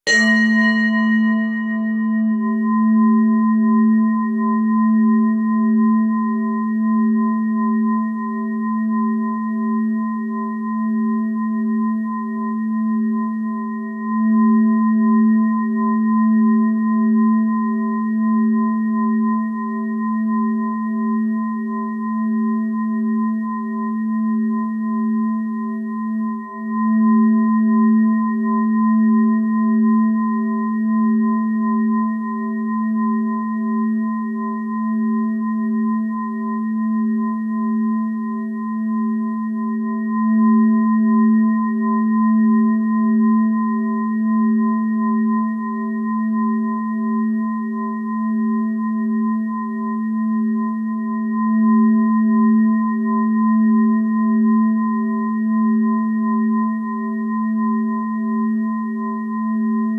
🧿 Evil eyes removal reiki ASMR The best way to protect yourself from negativity is by visualising a light shield around your being that will protect you from anything less than love.